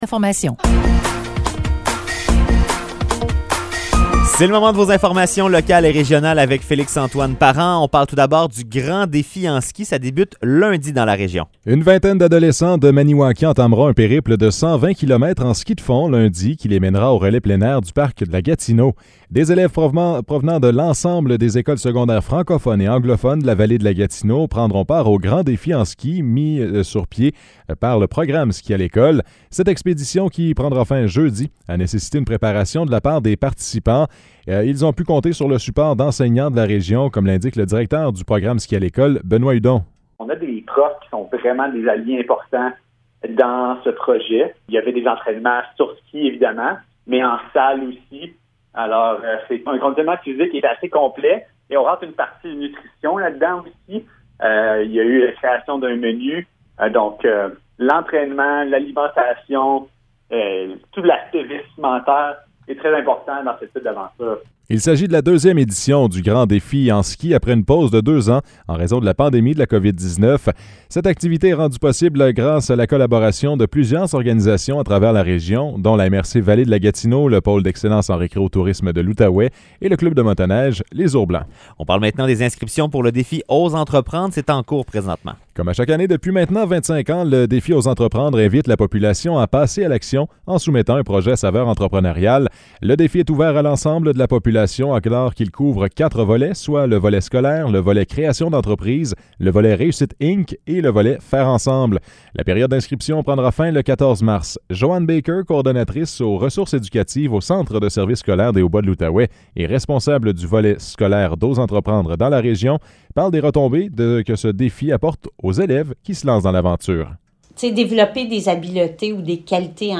Nouvelles locales - 17 février 2023 - 16 h